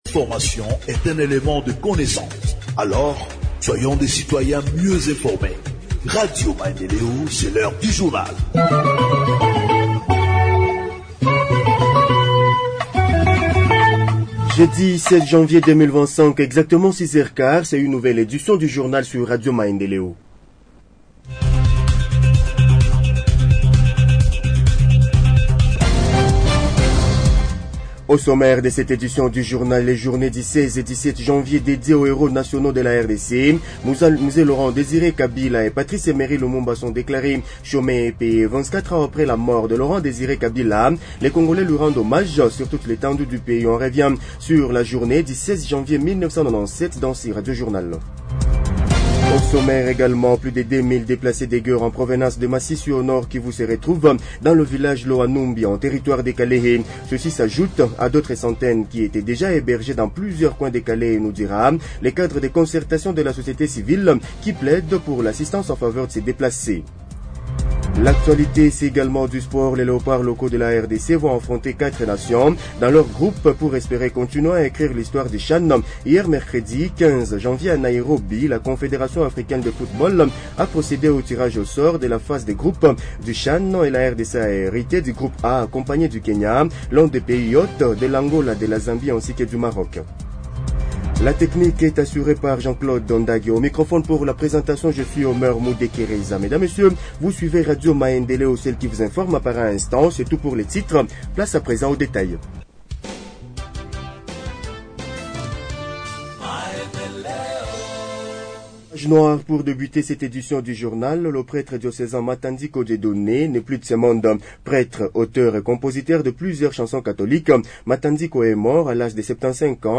Journal en Français du jeudi 16 janvier 2025 – Radio Maendeleo